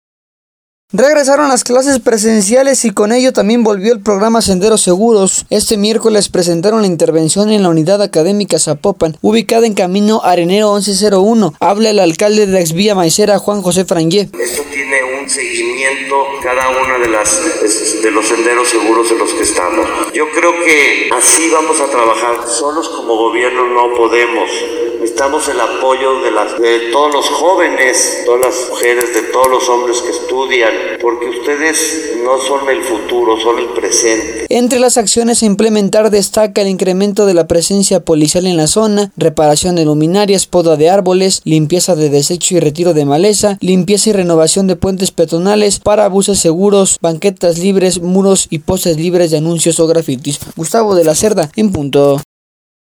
Regresaron las clases presenciales, y con ello también volvió el programa Senderos Seguros a Zapopan, y este miércoles presentaron la intervención en la Unidad Académica Zapopan, ubicada en Camino Arenero 1101, habla el alcalde Juan José Frangie: